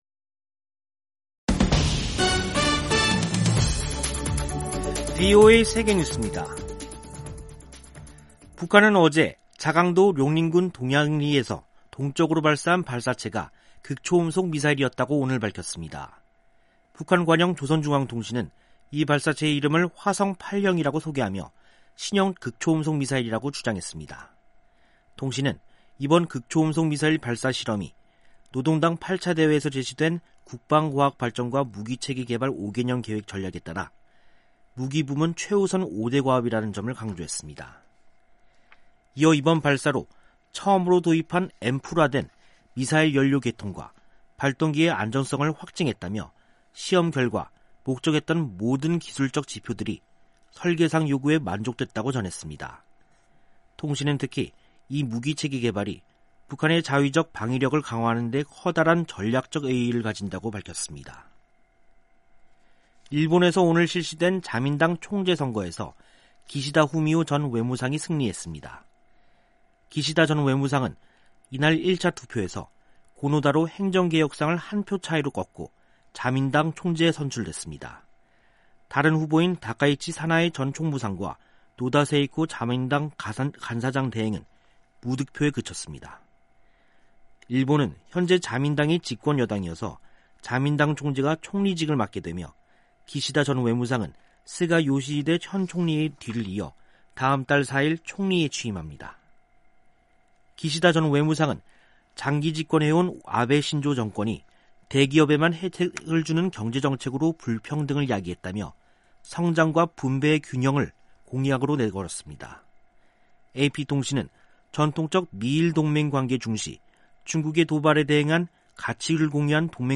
세계 뉴스와 함께 미국의 모든 것을 소개하는 '생방송 여기는 워싱턴입니다', 2021년 9월 29일 저녁 방송입니다. '지구촌 오늘'에서는 기시다 후미오 전 자민당 정조회장이 일본 차기 총리로 정해진 소식, '아메리카 나우'에서는 미 연방정부 셧다운 우려가 높아지는 이야기 전해드립니다.